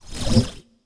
sci-fi_shield_device_small_02.wav